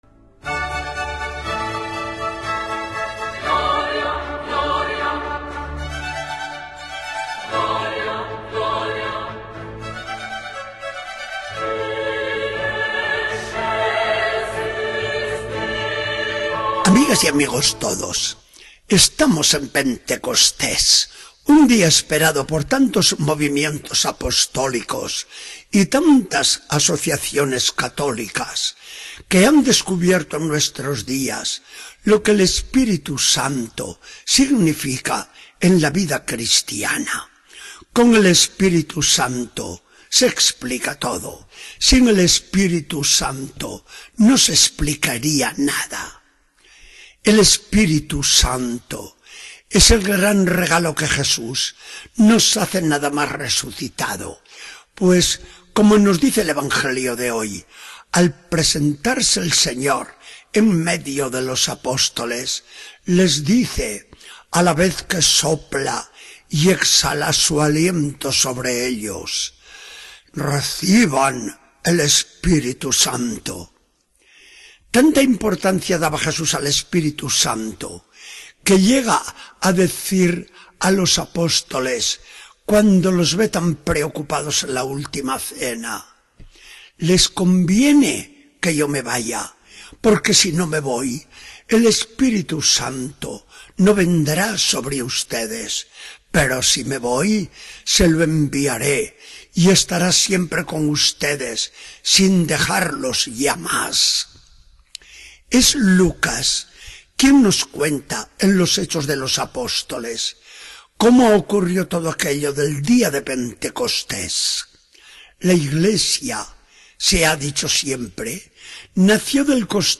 Charla del día 8 de junio de 2014. Del Evangelio según San Juan 20, 19-23.